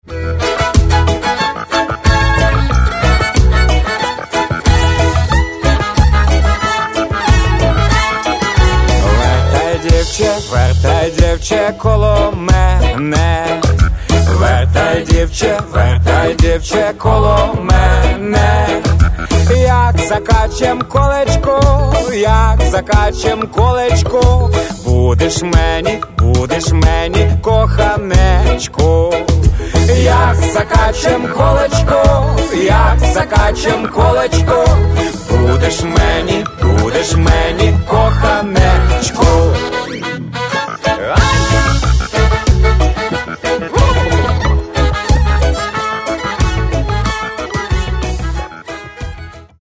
Catalogue -> Rock & Alternative -> Reggae